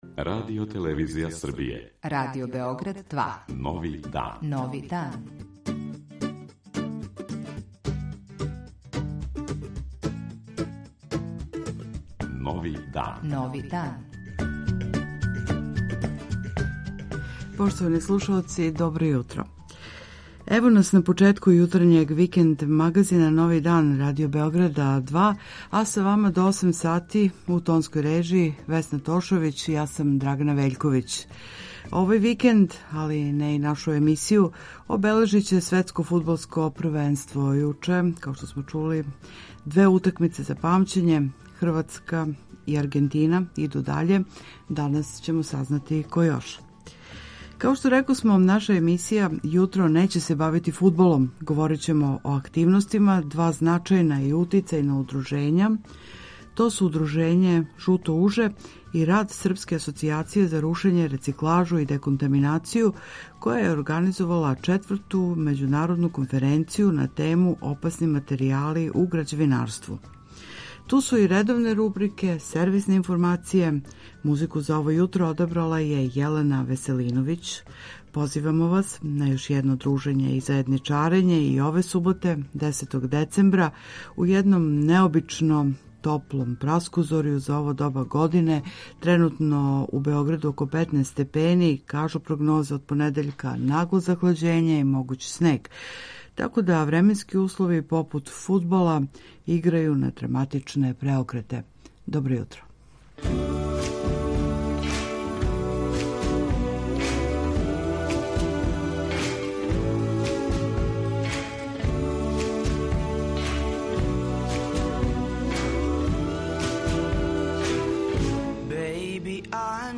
Чућете и друге редовне рубрике и сервисне информације.